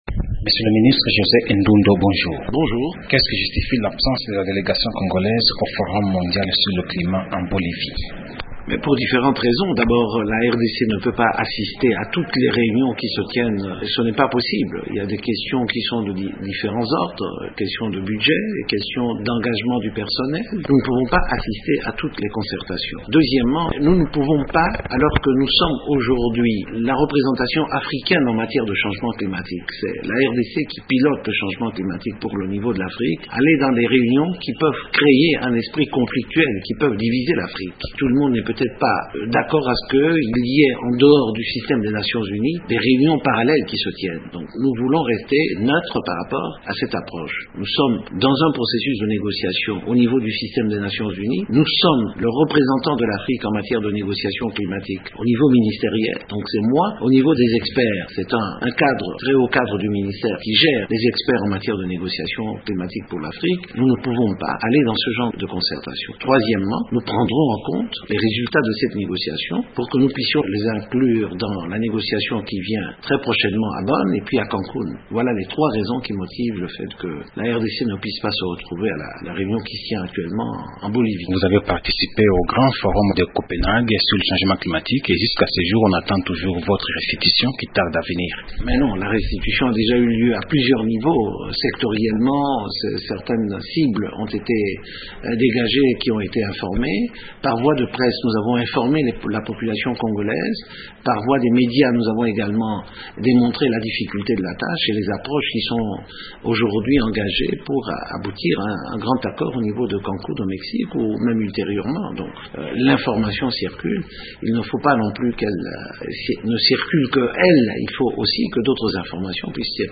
José Endundo, ministre congolais de l'Environnement